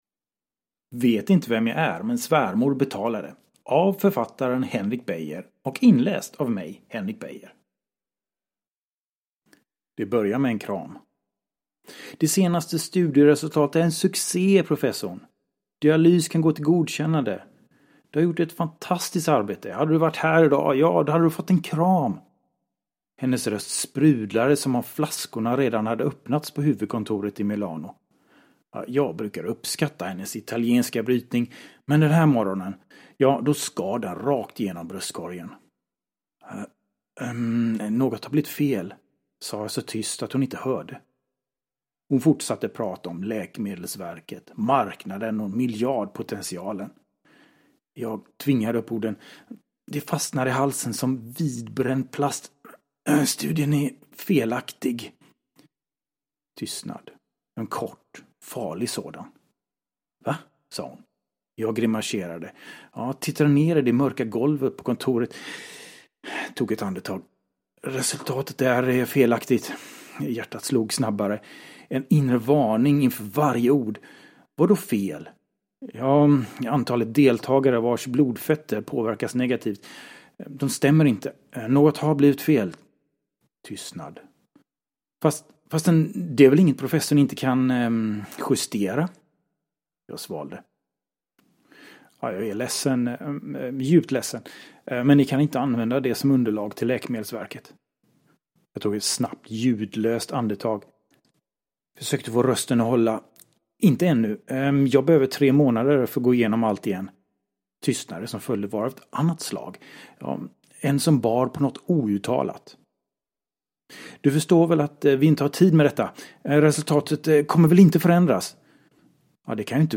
Vet inte vem jag är, men svärmor betalade – Ljudbok